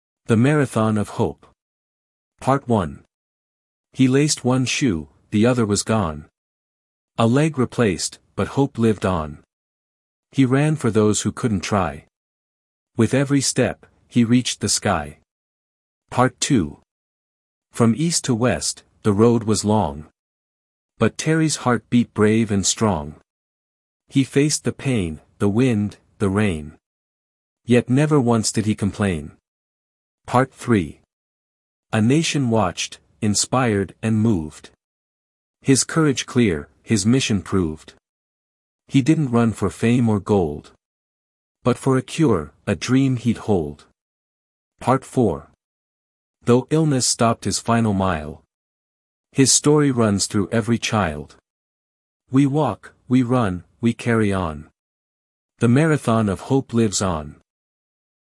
Choral Reading: The Marathon of Hope
A rhythmic group poem for 4 reading parts.